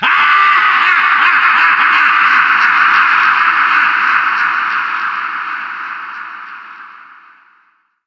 doom_laugh2.mp3